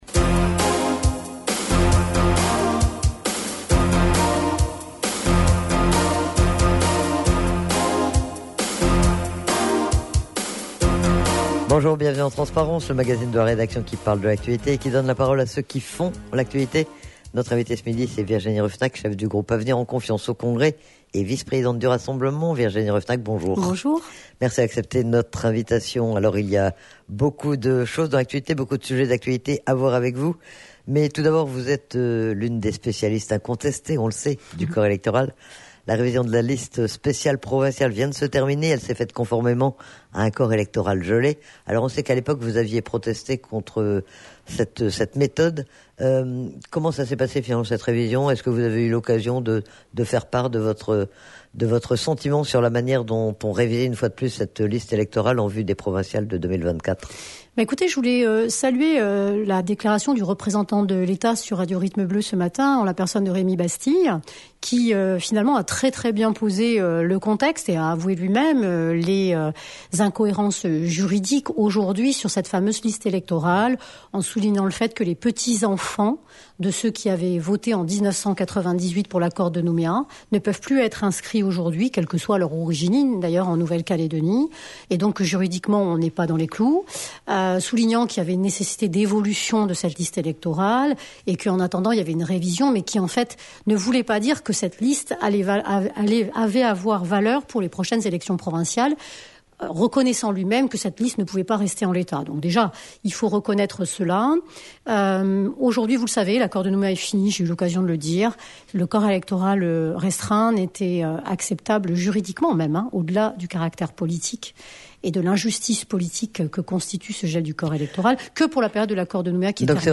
Virginie Ruffenach a été interrogée sur la révision du corps électoral, sur l'avenir institutionnel, sur les discussions bilatérales et trilatérales mais aussi sur la tournée de retour de Paris ou encore sur les élections sénatoriales.